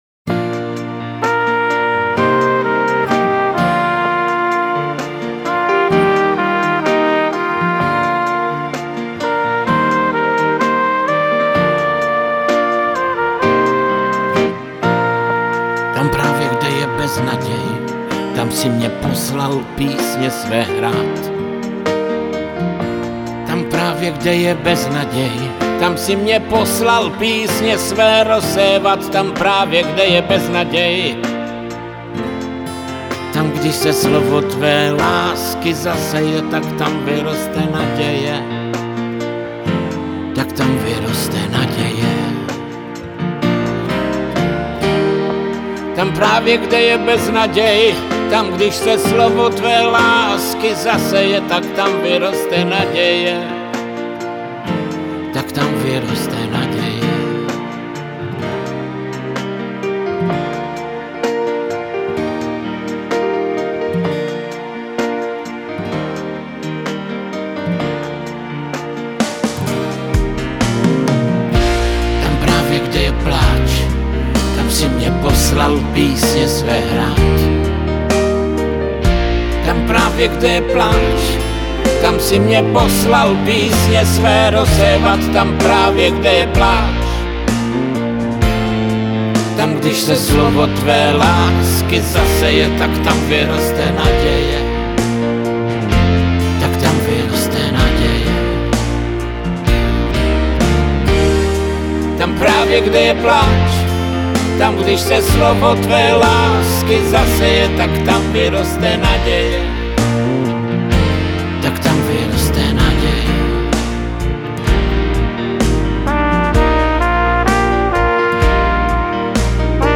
Křesťanské písně
Evangelizační písně